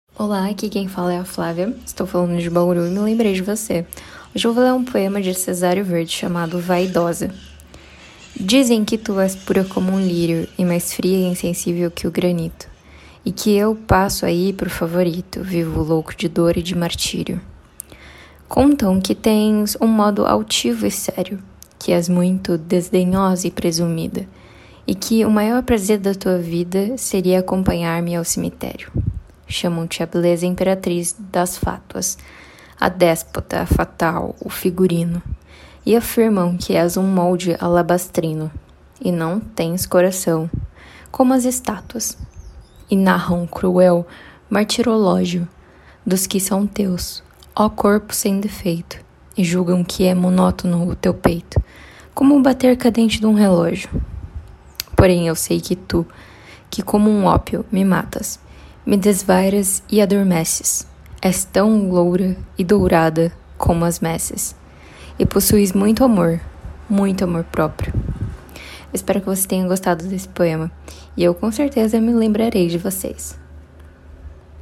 Poema Português